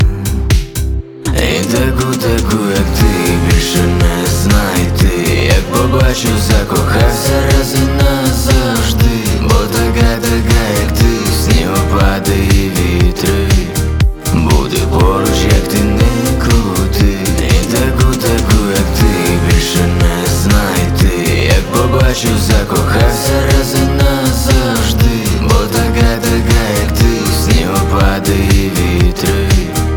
Жанр: Поп музыка / Украинские